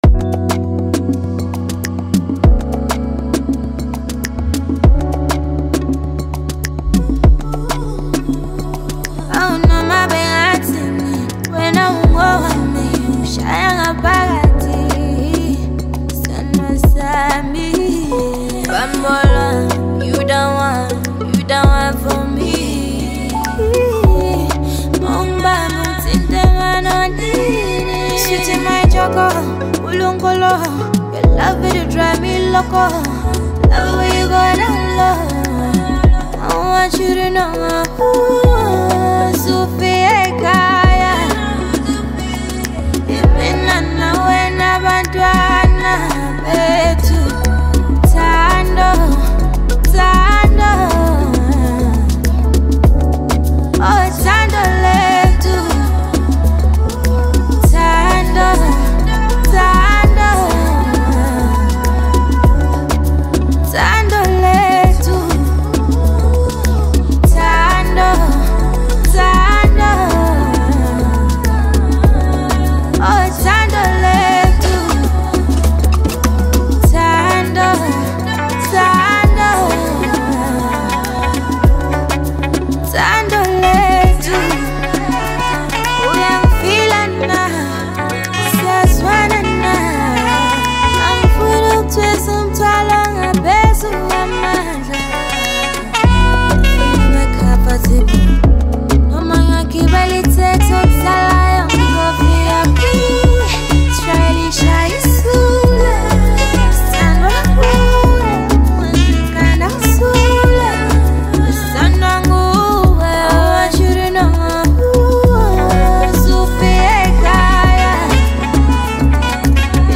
The incredible talented South African singer-songwriter
heartfelt and soulful track
powerful vocals take center stage
With its infectious rhythm and catchy melodies